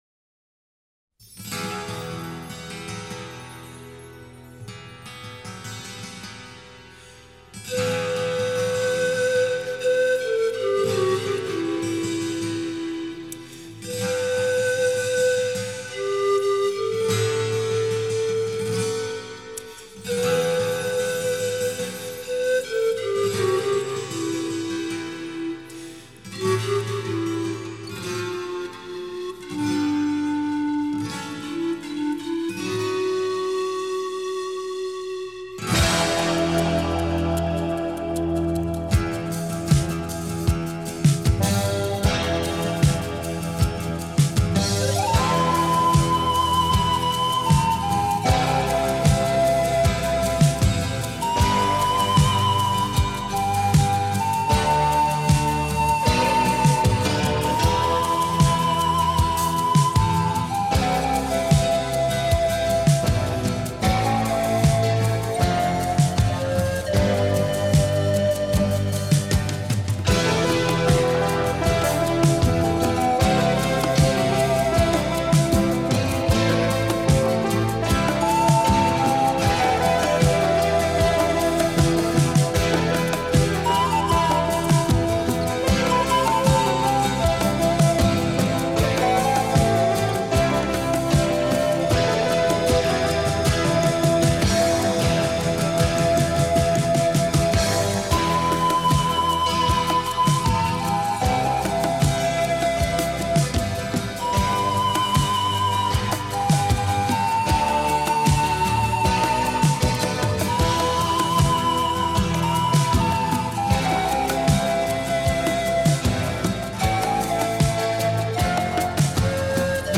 была панфлейта.